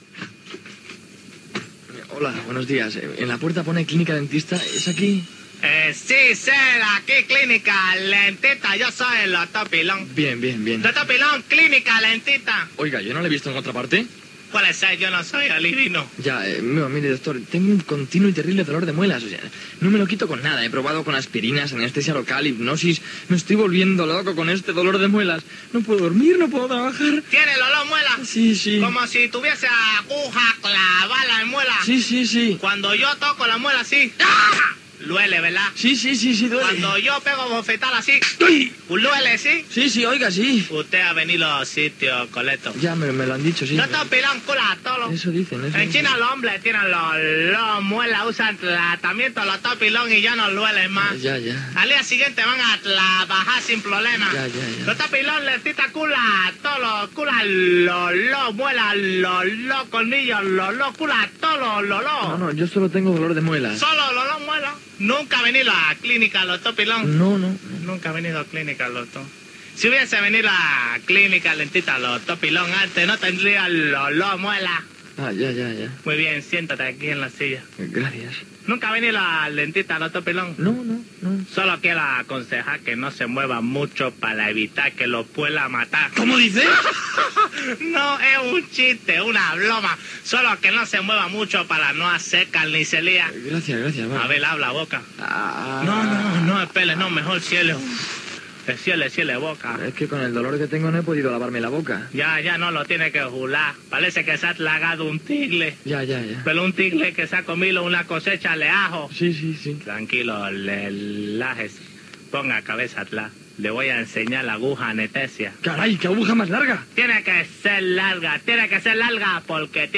"Sketch" humorístic "El dentista Lotó Pilón"
Entreteniment